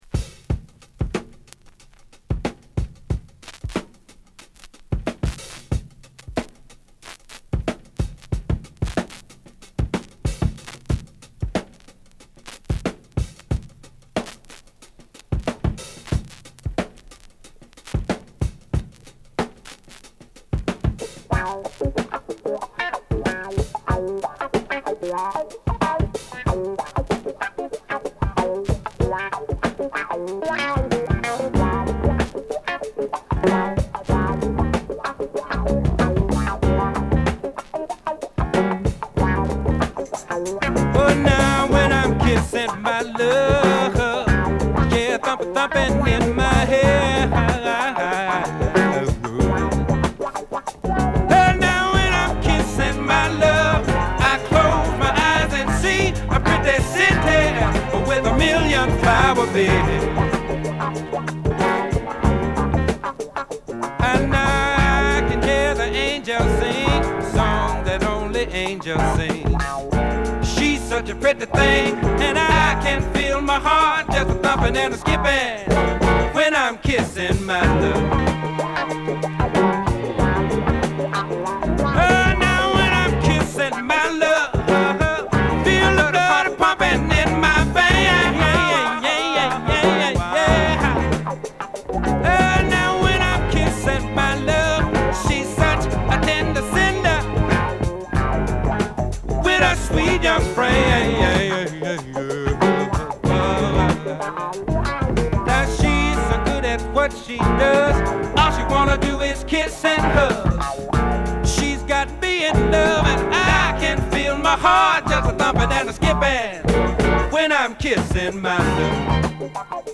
独特のフォーキーなサウンドと郷愁を帯びたヴォーカルで
＊盤面奇麗ですが、ジャリっとノイズ出る箇所が有ります。